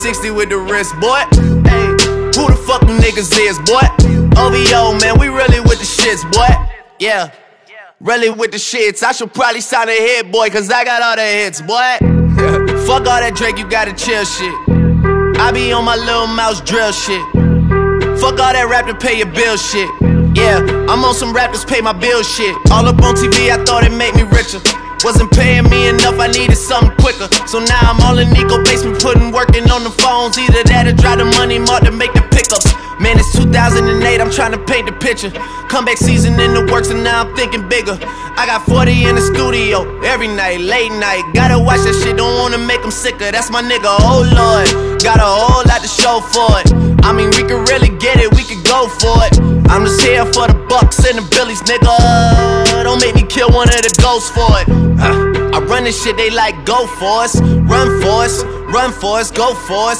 Flex 55 with that lumpy idle